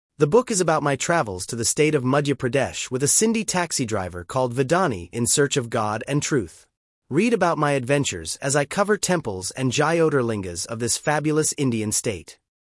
PLAY VOICE SAMPLE